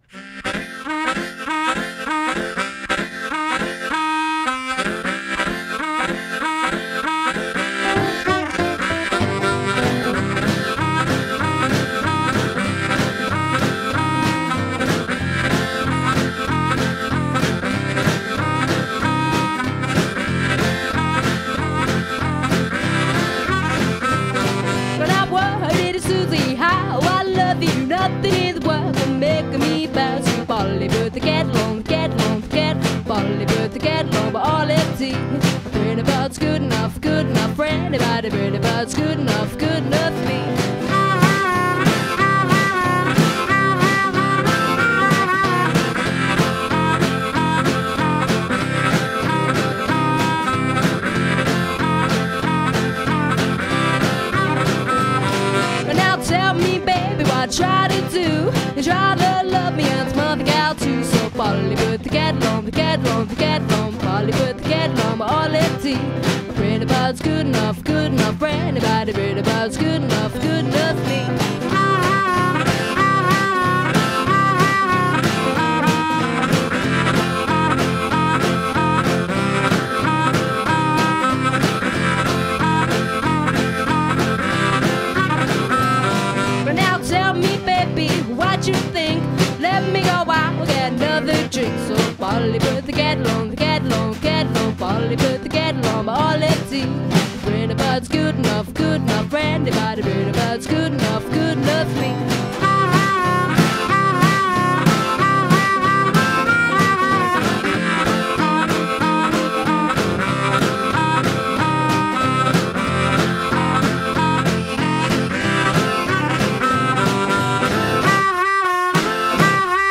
a list of songs for Open Mic